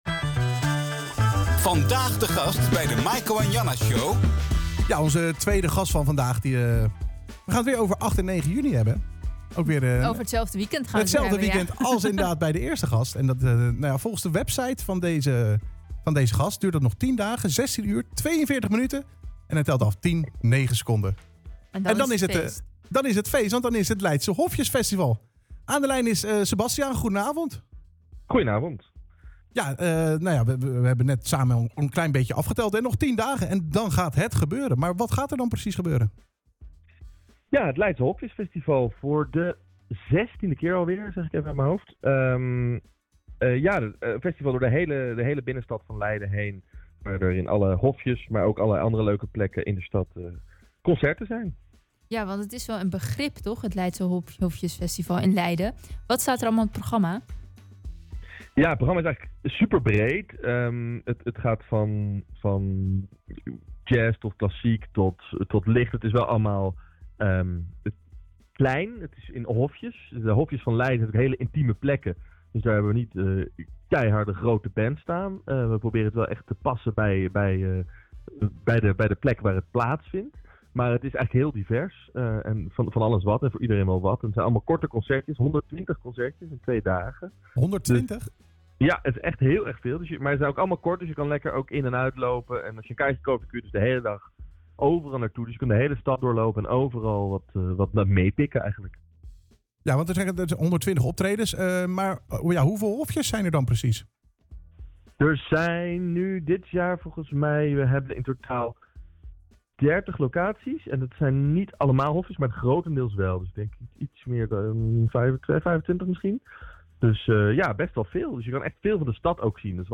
schoof woensdagavond telefonisch aan